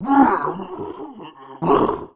monster / greenfrog_general / dead_1.wav
dead_1.wav